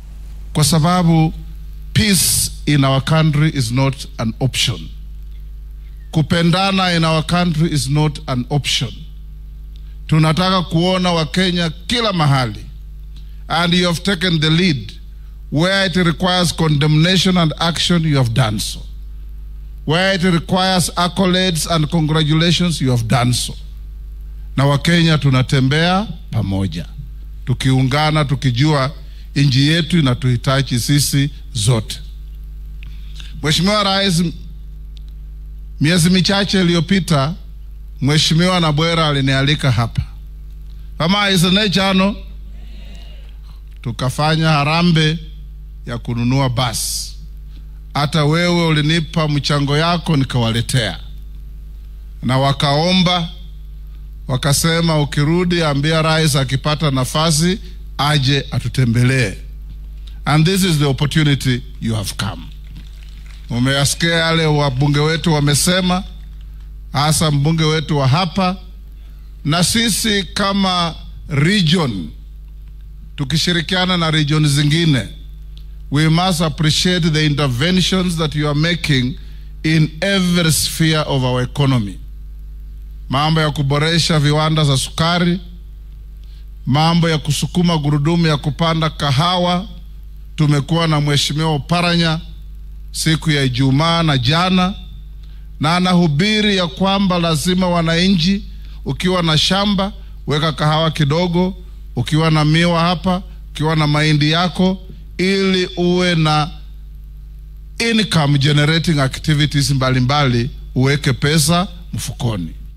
Madaxweynaha dalka William Ruto ayaa ka qayb galay xaflad kaniisadeed oo ka dhacday dugsiga Friends Comprehensive School ee ku yaala magaalada Lugari, ee ismaamulka Kakamega.
Mr Wetangula oo ka hadlay goobta uu ku sugnaa hoggaamiyaha ugu sarreeya ee dalka ayaa Kenyaanka ugu baaqay midnimo.